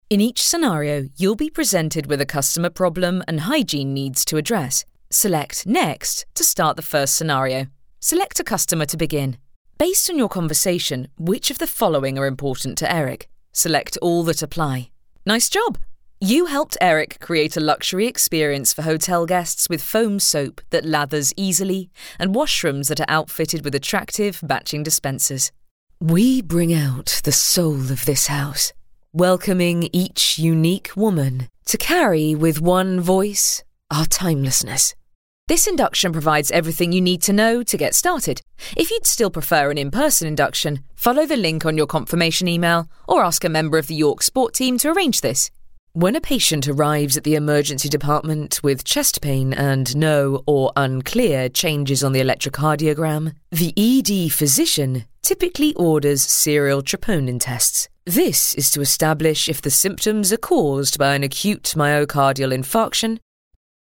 E-learning
Professionally built studio.
Mezzo-Soprano
WarmConversationalFriendlyClearProfessionalConfidentWitty